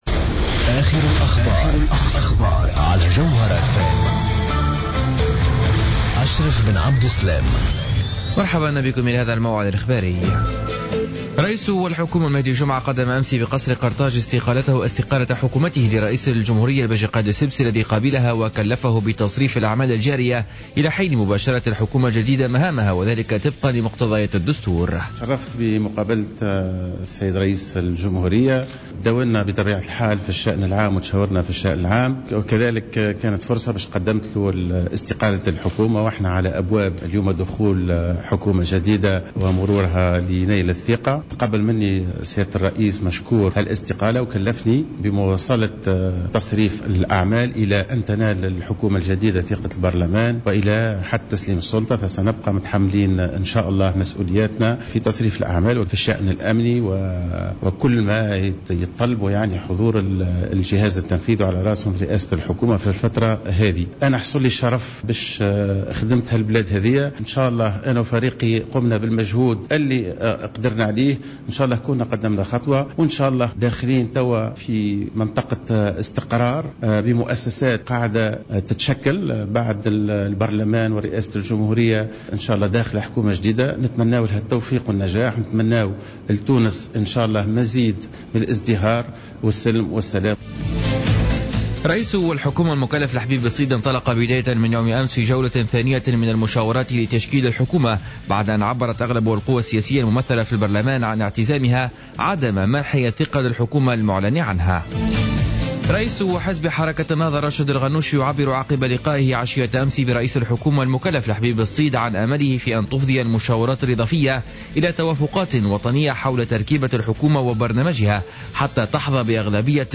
نشرة أخبار منتصف الليل ليوم 27-01-15